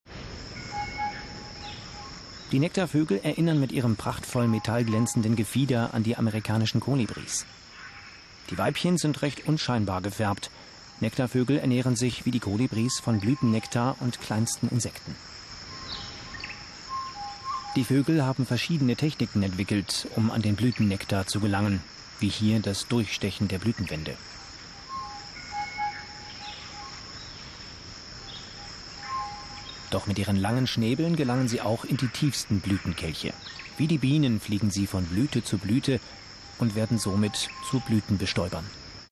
Deutscher Sprecher für Radio & Fernsehen Industriefilme, Werbung, Reportagen, Dokumentationen, Overvoice, Nachrichten, Trailer mittlere Tonlage, Referenzen u.a. ARD, RB, NDR, BMW, Arte, Merz Pharmaceuticals, Messe Bremen, Kunsthalle Bremen
Kein Dialekt
Sprechprobe: Sonstiges (Muttersprache):